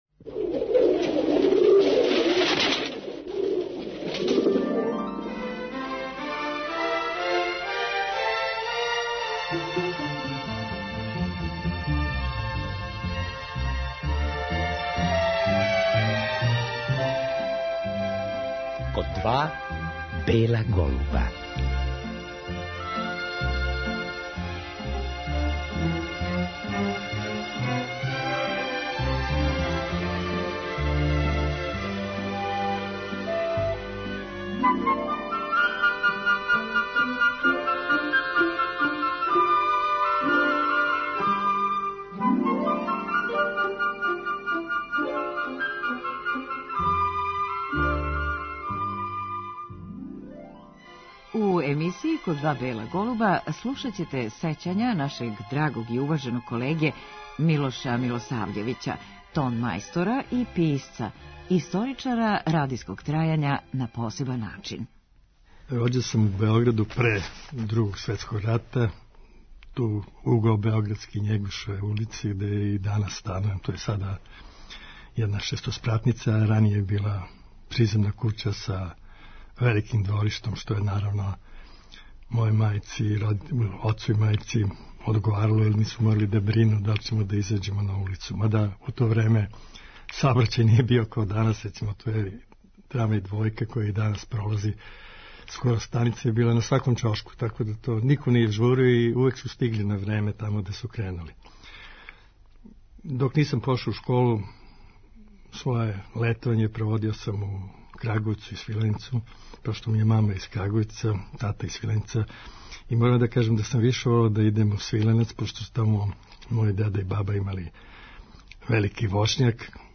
Много тога је забележио на магнетофонској траци а ми ћемо слушати како су неке од анегдота казивали ствараоци програма.